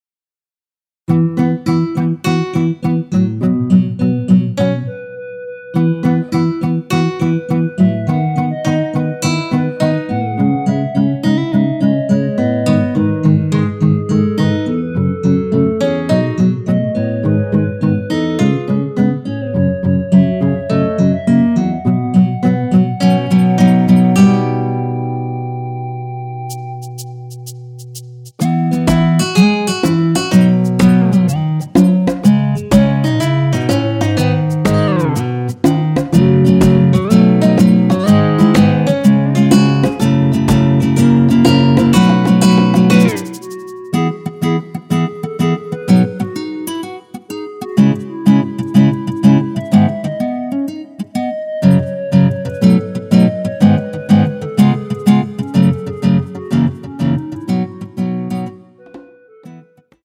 전주 없이 시작 하는곡이라 노래 하시기 편하게 전주 2마디 많들어 놓았습니다.(미리듣기 확인)
원키 멜로디 포함된 MR입니다.
앞부분30초, 뒷부분30초씩 편집해서 올려 드리고 있습니다.
중간에 음이 끈어지고 다시 나오는 이유는